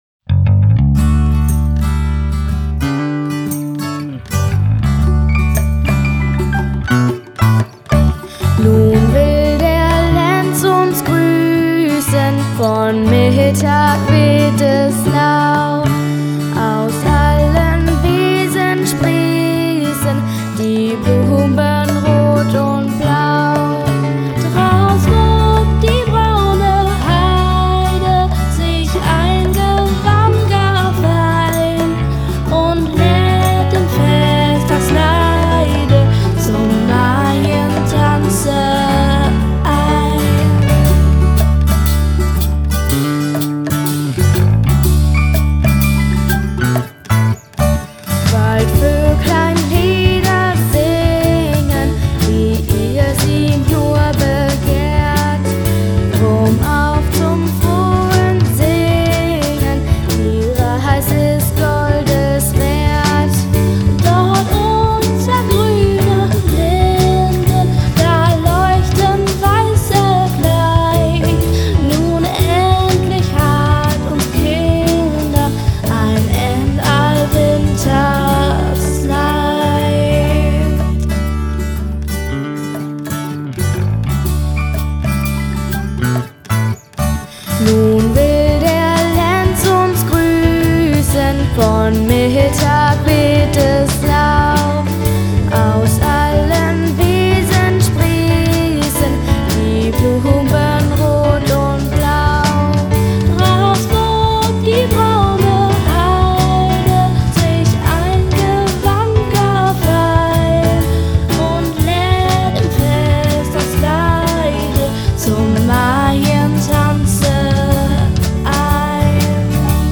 Ein weiteres deutsches Volkslied aus dem 18. Jahrhundert.